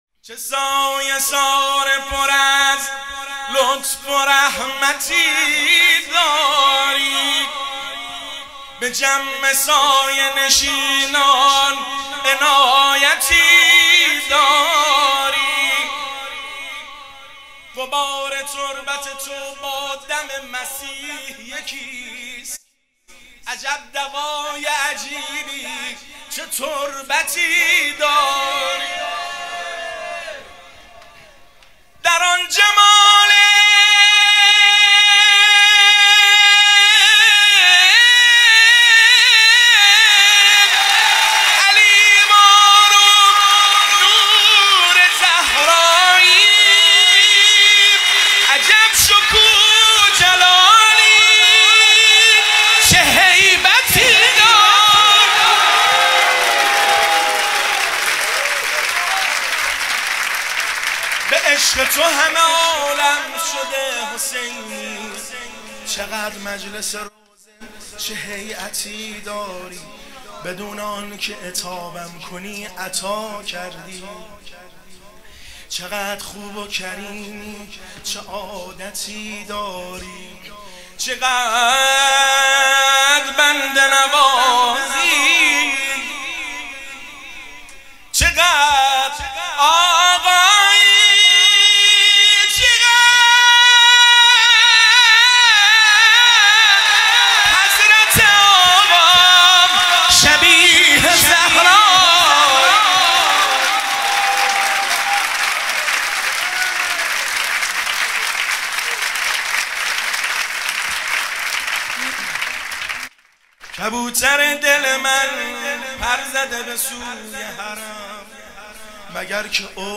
میلاد امام حسین(ع) | هیئت حسینی طاهر آباد کاشان